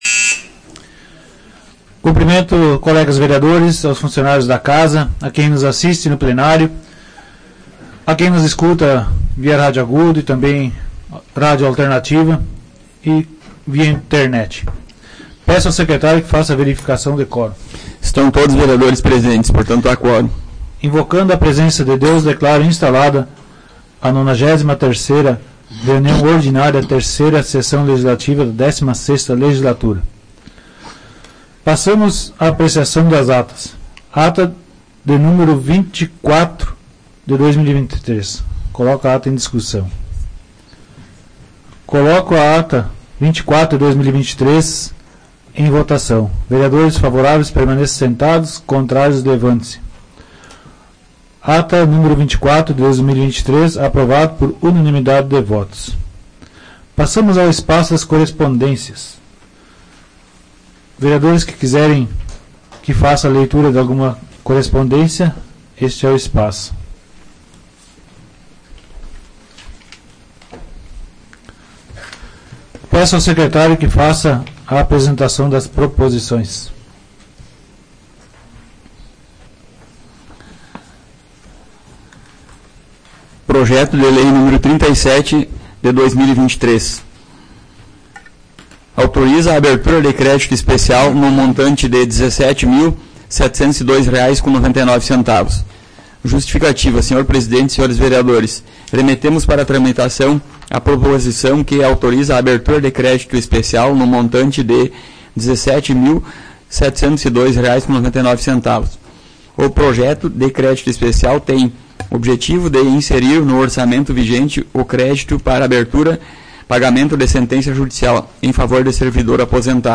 Áudio da 93ª Sessão Plenária Ordinária da 16ª Legislatura, de 12 de junho de 2023